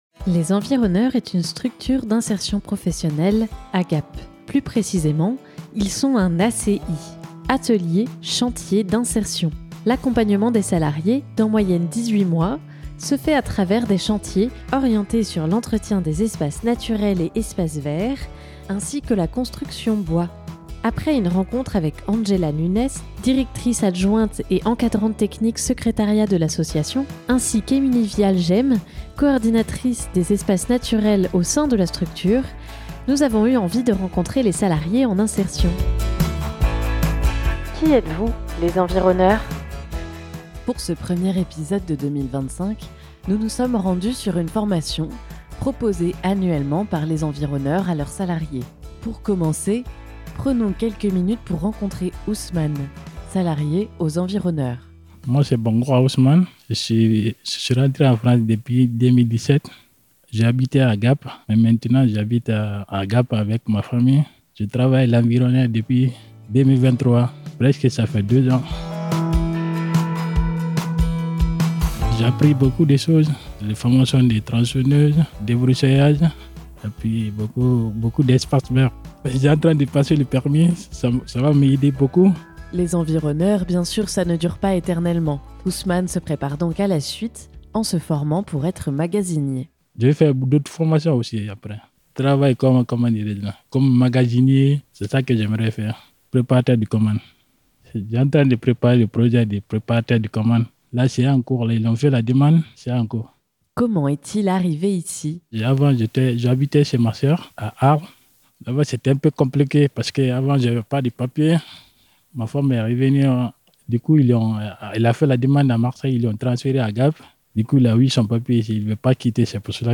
Dans ce troisième épisode, nous nous sommes rendus sur une formation tronçonneuse-débroussailleuse. Ces formations sont organisées en début d'année, pour permettre aux salarié·e·s de travailler en toute sécurité - l'un des points d'honneur des Environneurs.